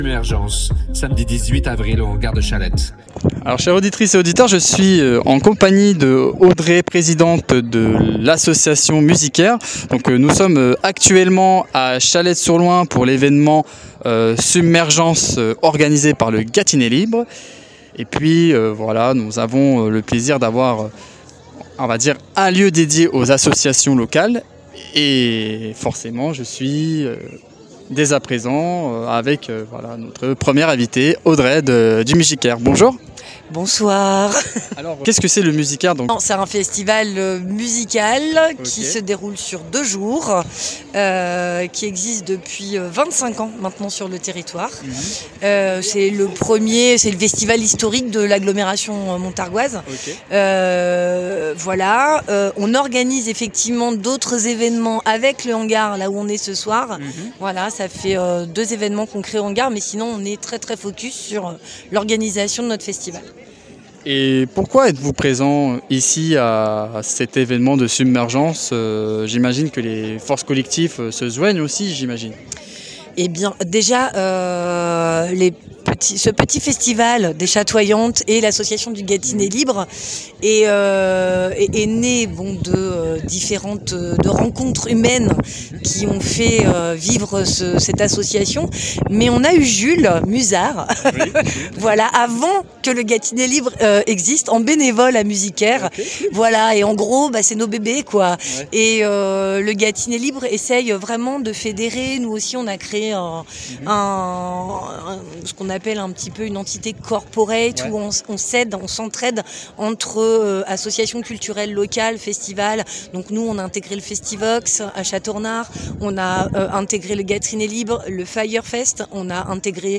Dans cet épisode, on vous emmène au cœur du village associatif de Submergence, un événement porté par Gâtinais Libre au Hangar de Châlette-sur-Loing.